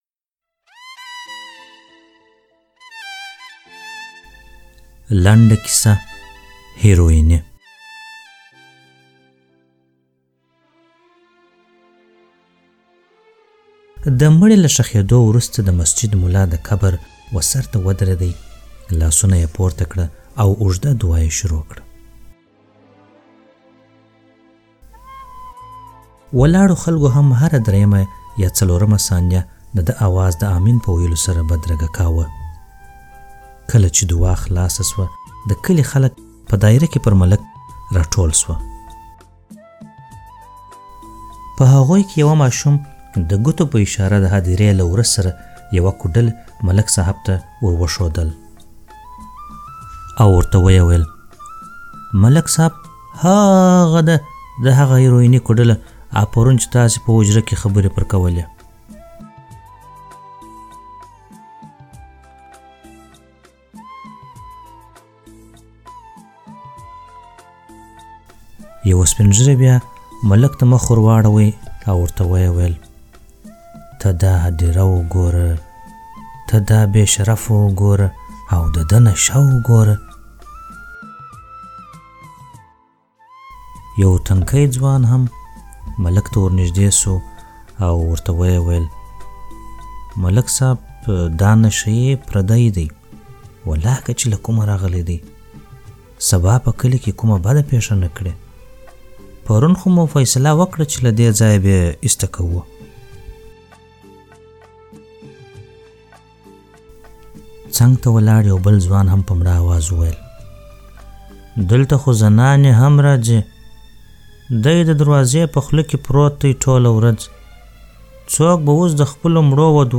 لنډه کيسه هيرويني
ږغیزي لنډي کیسې